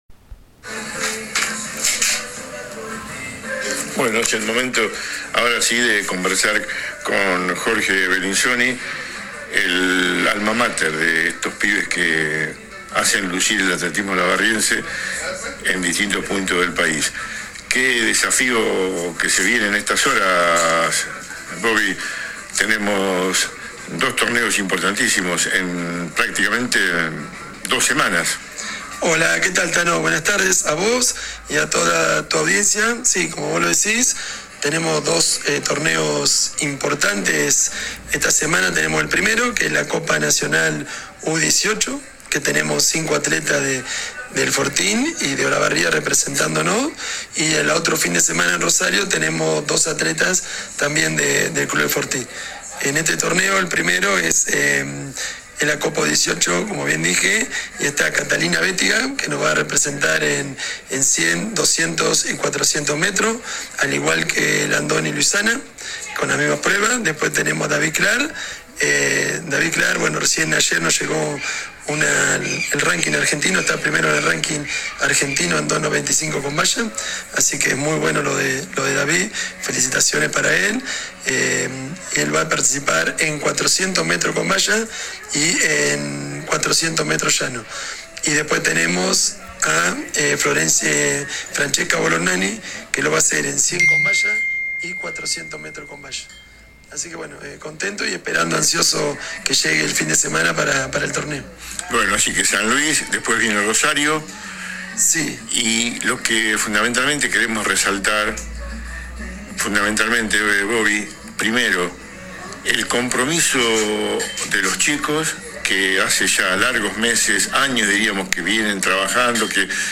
AUDIOS DE LAS ENTREVISTAS